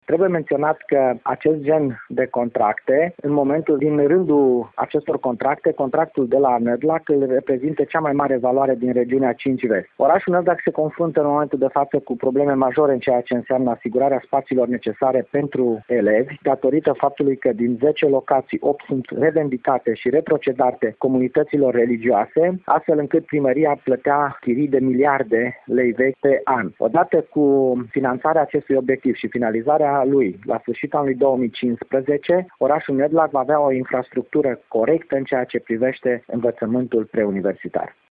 Preşedintele Consiliului Judeţean Arad, Nicolae Ioţcu, precizează că orașul Nădlac se confruntă cu probleme serioase în ceea ce privește asigurarea spațiilor de funcționare pentru activitățile educative.